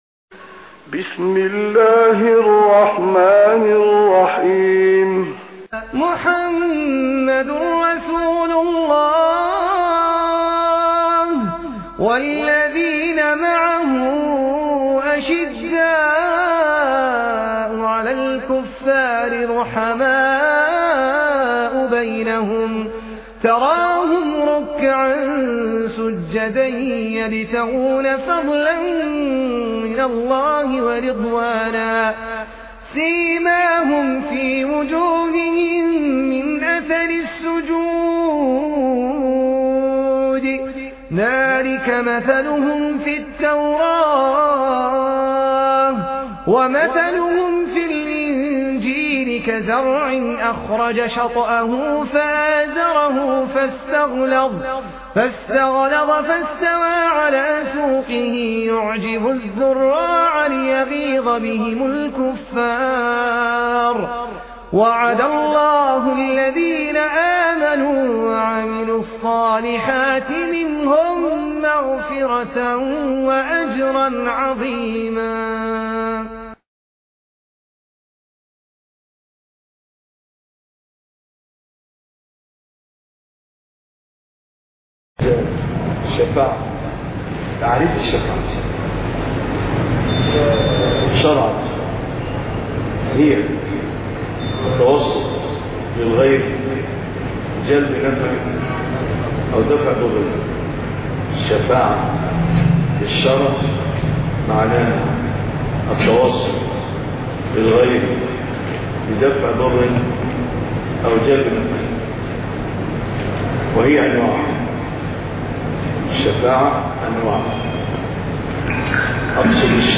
الدرس 29 ( شرح متن العقيدة الطحاوية )